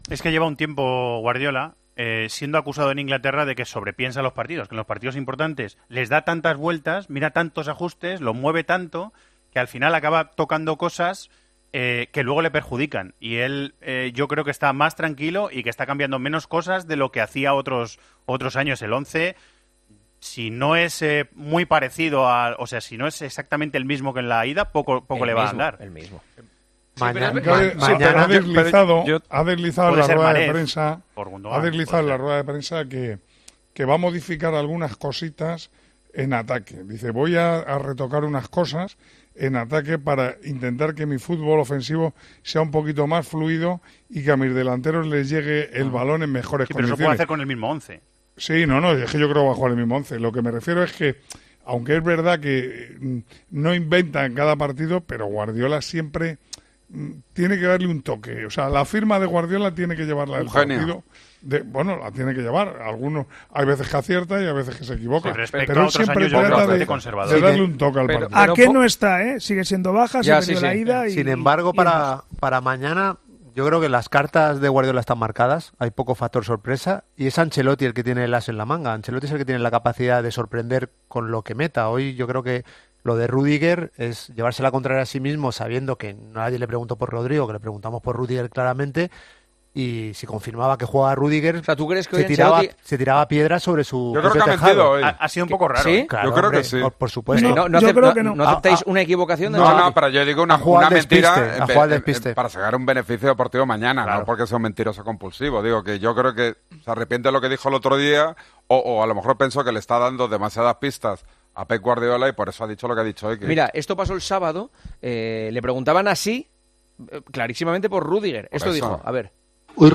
AUDIO: El narrador de los partidos del Real Madrid en Tiempo de Juego da su opinión sobre las palabras del entrenador italiano en rueda de prensa.